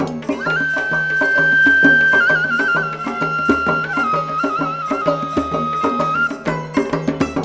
Index of /90_sSampleCDs/Zero G - Ethnic/Partition F/ZITHERLOOPS1
ZITHALOOP2-L.wav